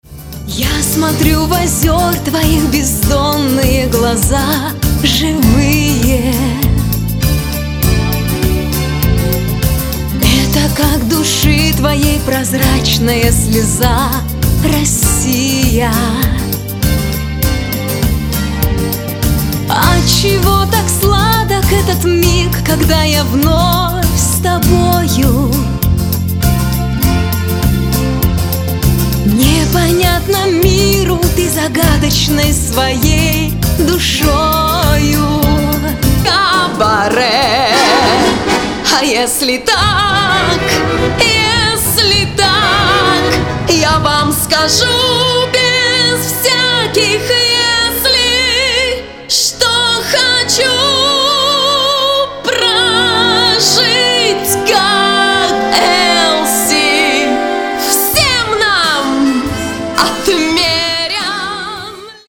Для примера можете послушать файл - фрагменты песен: первая - голос записан через ЕРР-102, вторая голос записан через ЕМР-124.
В некомпрессированном варианте такого нет.